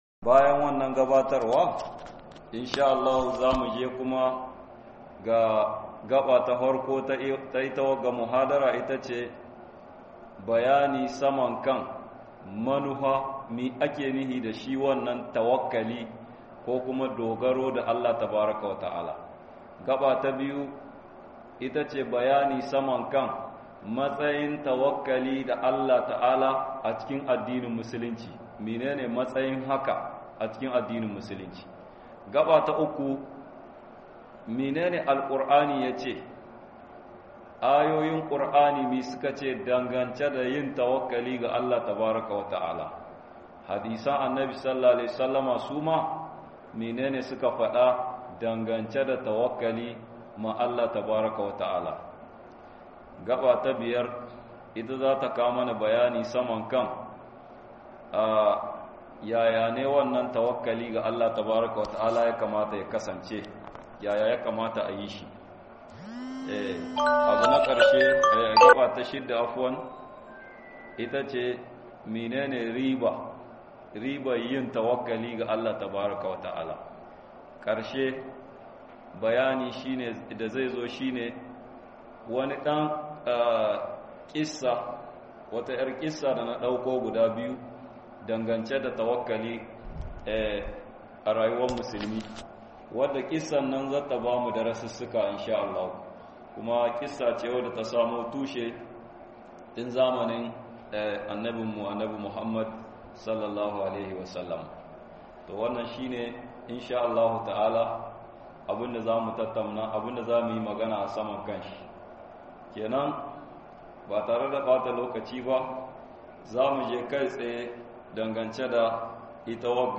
dogaro da Allah - MUHADARA